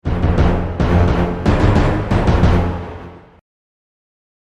Or if you have something foreboding to say, try some